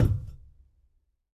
tbd-station-14/Resources/Audio/effects/footsteps/hull4.ogg at bc24a852f901bef23c739b9316dadd0e865f35c5